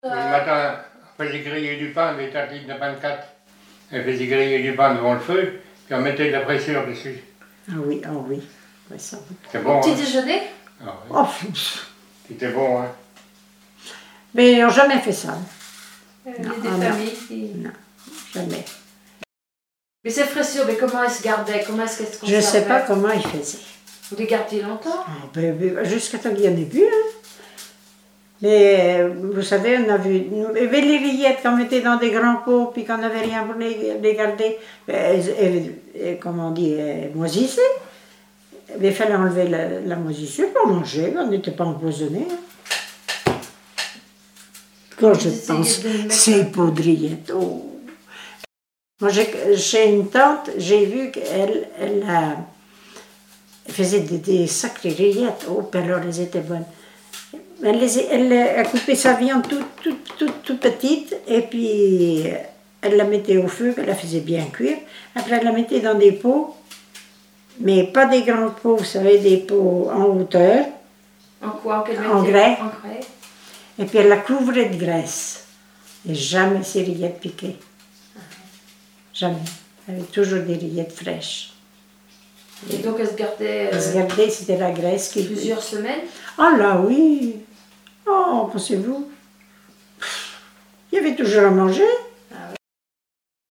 Enquête Arexcpo en Vendée-Pays Sud-Vendée
Témoignages sur les tâches ménagères